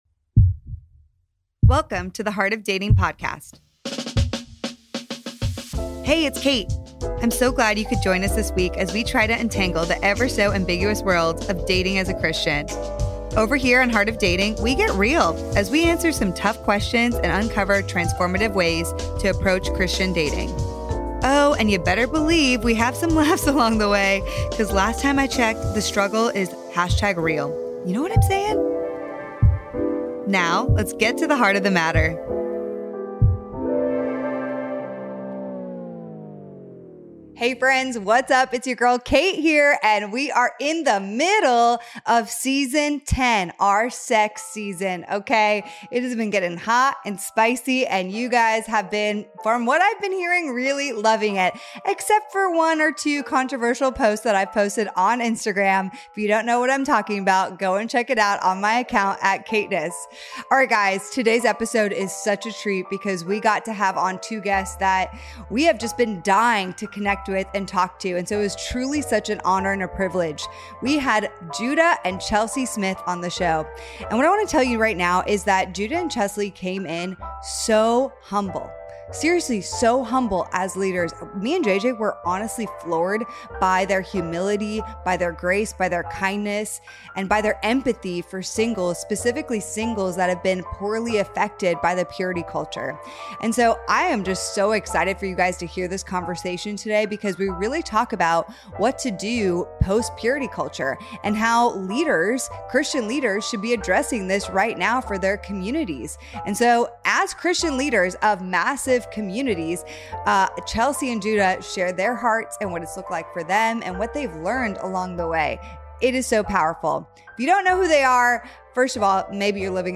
a real and raw conversation about post purity culture and sexual ethic today